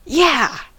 yeah-interjection: Wikimedia Commons US English Pronunciations
En-us-yeah-interjection.WAV